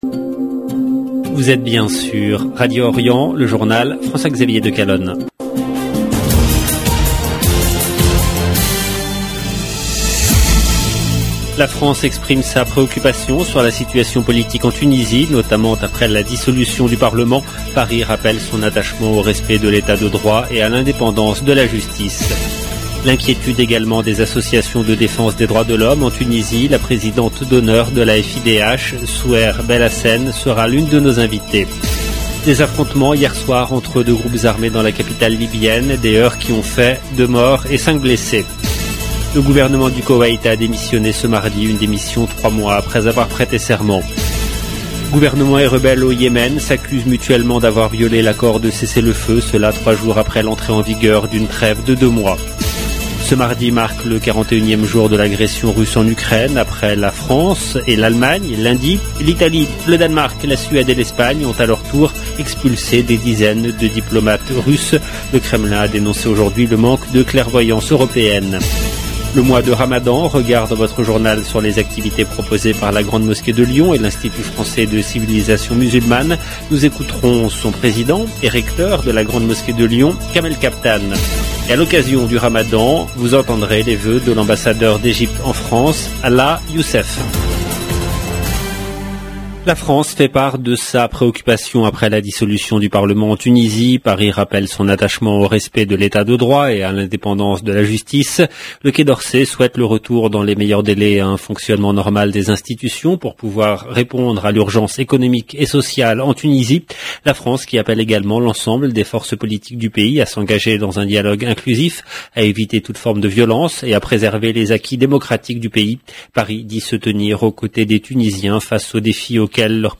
LB JOURNAL EN LANGUE FRANÇAISE
A l'occasion du ramadan nous écouterons les voeux de l'Ambassadeur d'Egypte en France, Alaa Youssef. 0:00 16 min 43 sec